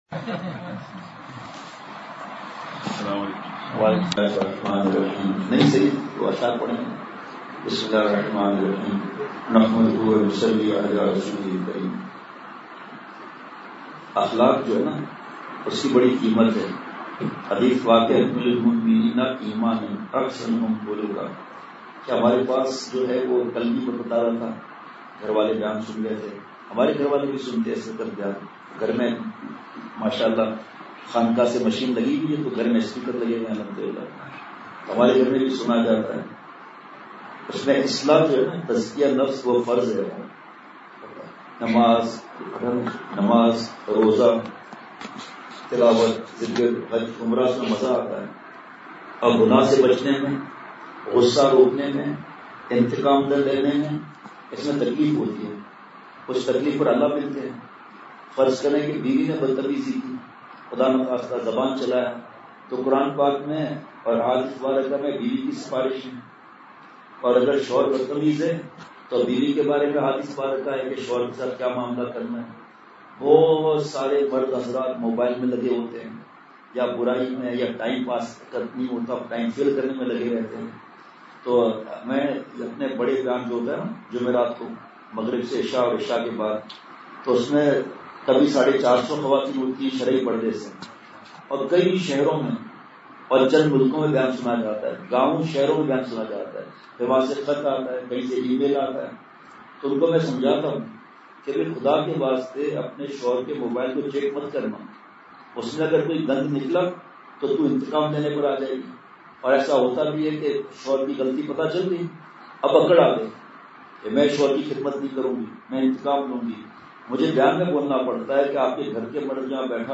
Please download the file: audio/mpeg مجلس محفوظ کیجئے اصلاحی مجلس کی جھلکیاں بمقام :۔ رہائش گاہ پشاور
بیان کے اخر میں ہنسی کی مجلس ہوئی۔